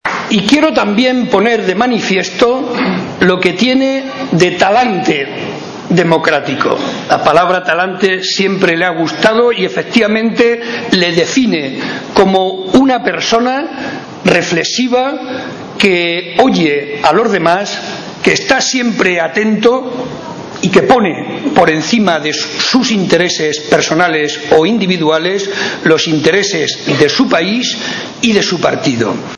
Momento del acto celebrado en Campo de Criptana.